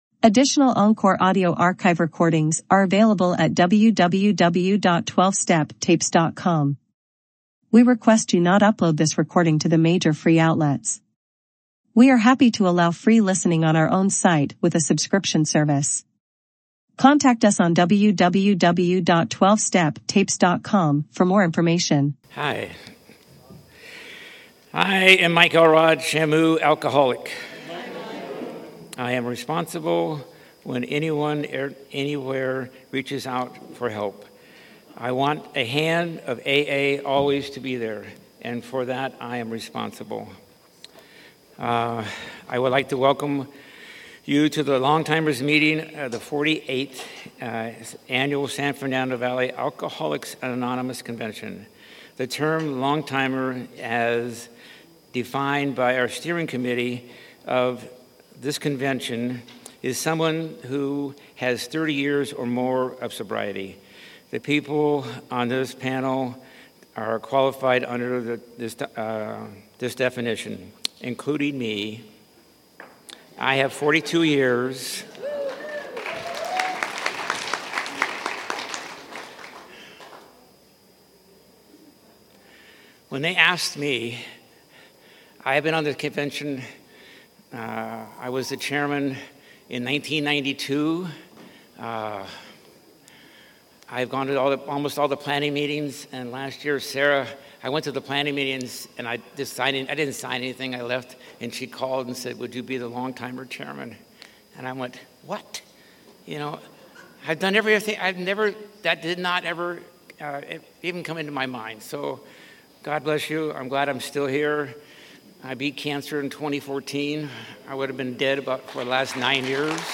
San Fernando Valley AA Convention 2024 - Let Go and Let God